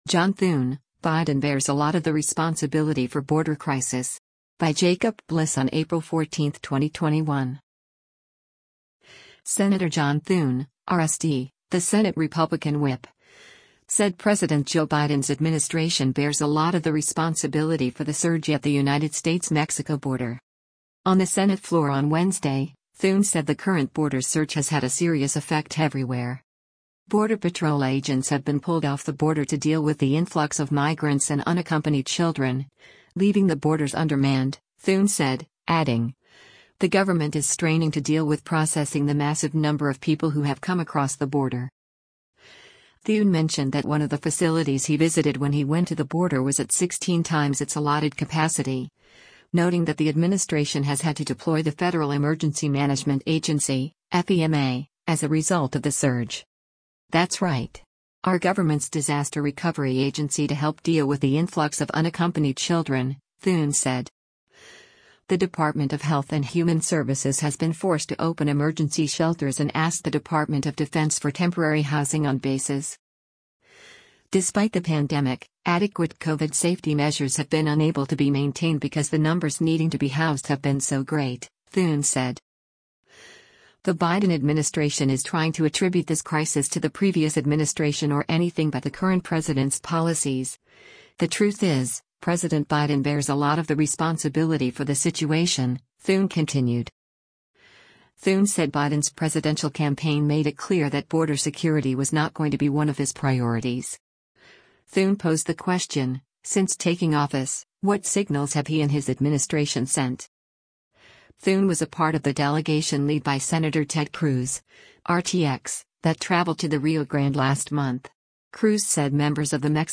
On the Senate floor on Wednesday, Thune said the current border surge has had a serious effect everywhere.